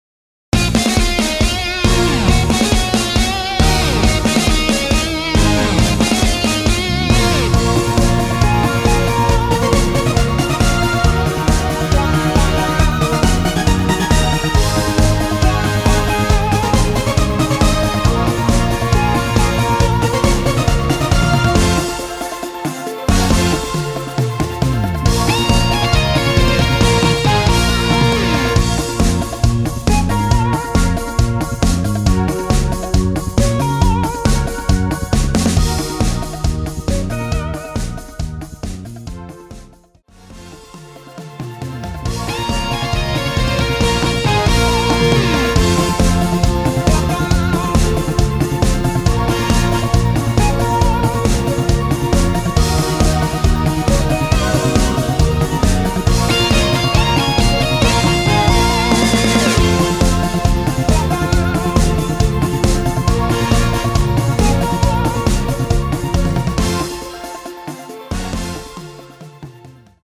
대부분의 여성분이 부르실수 있는 키로 제작 하였습니다.
원키에서(+4)올린 MR입니다.
Bbm
앞부분30초, 뒷부분30초씩 편집해서 올려 드리고 있습니다.
중간에 음이 끈어지고 다시 나오는 이유는